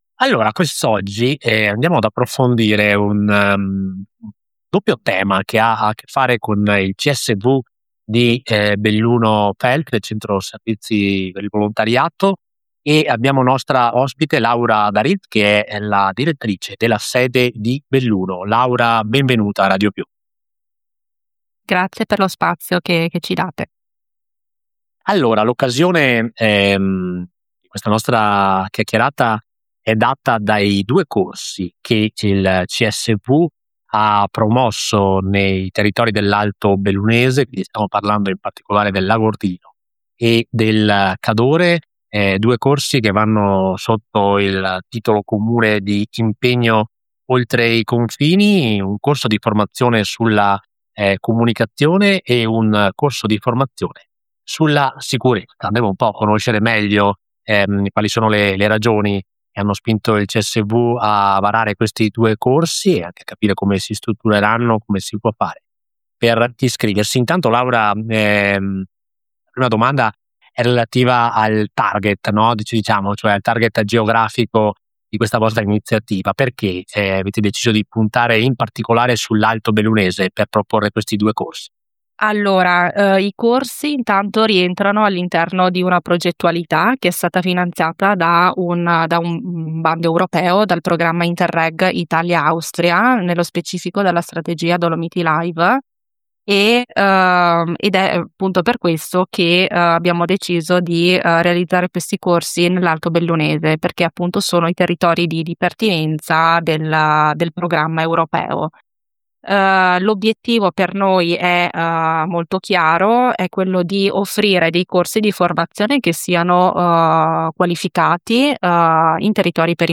LO SPECIALE ALLA RADIO